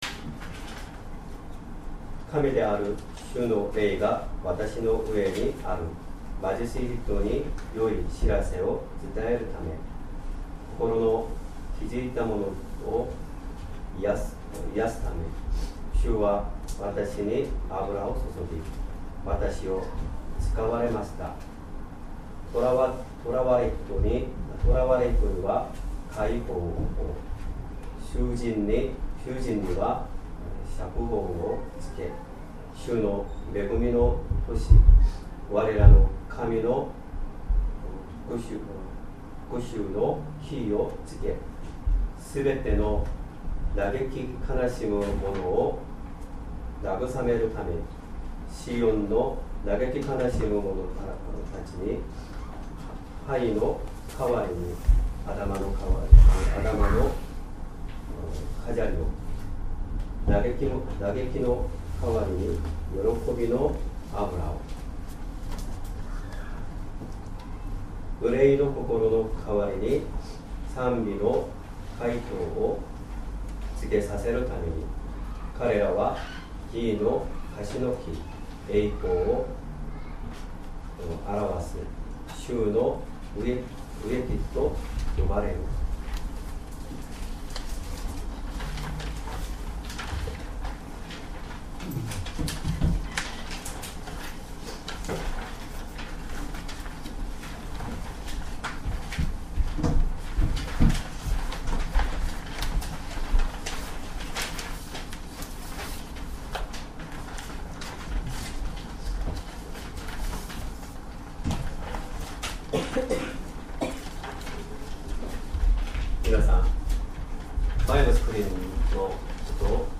Sermon
2025年12月21日 主日礼拝 説教 「良い知らせを伝えるために」 聖書 イザヤ 61章 1-3節 61:1 【神】である主の霊がわたしの上にある。